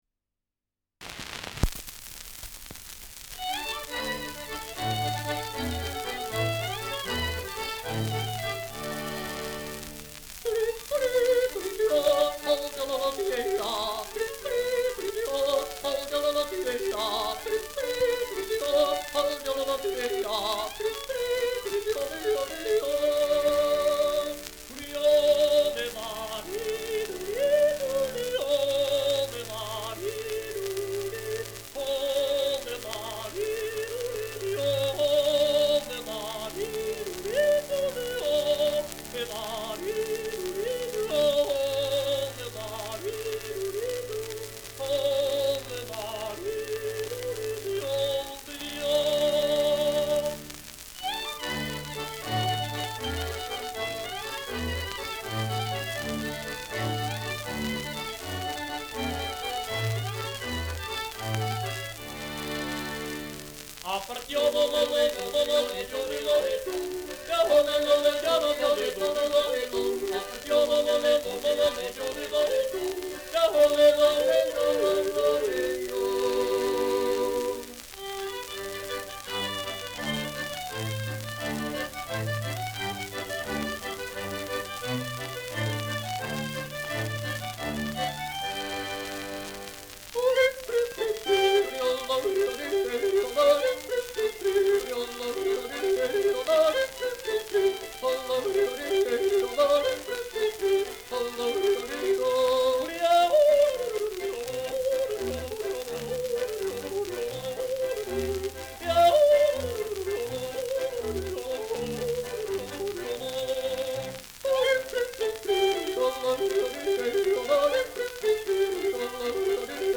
Schellackplatte
[Camden] (Aufnahmeort)